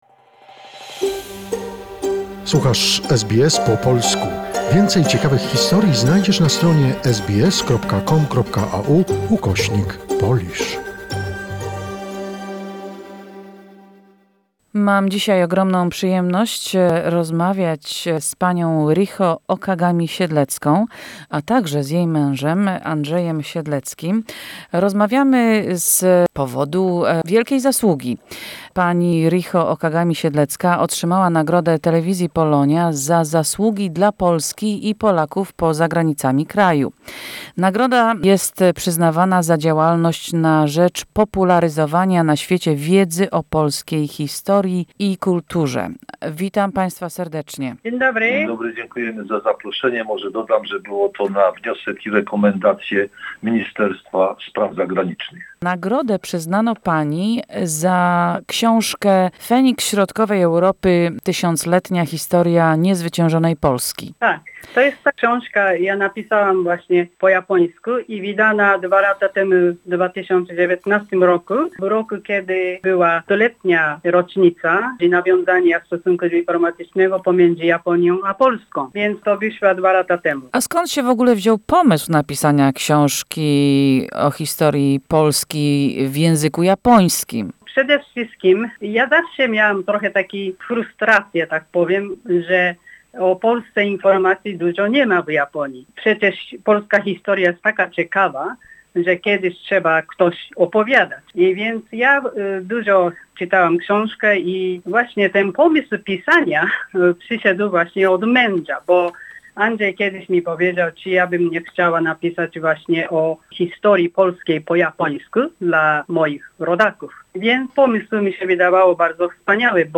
Oto rozmowa o książce